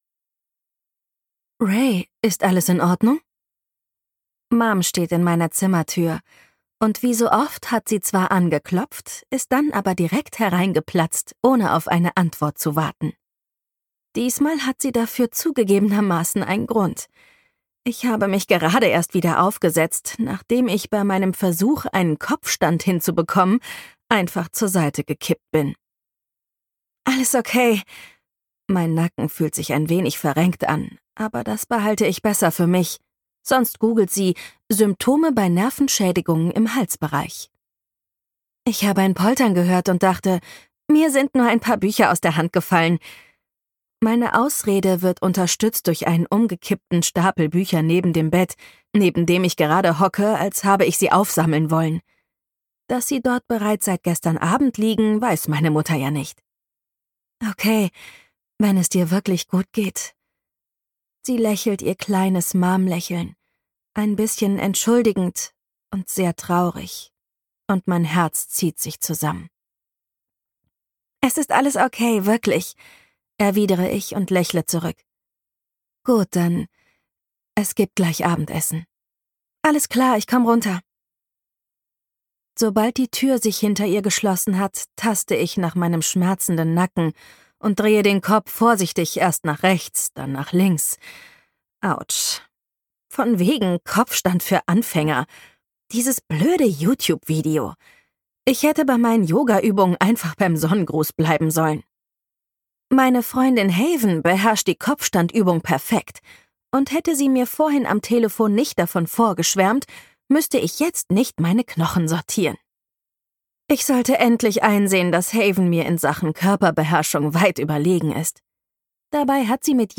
Gekürzt Autorisierte, d.h. von Autor:innen und / oder Verlagen freigegebene, bearbeitete Fassung.
Hörbuchcover von Free like the Wind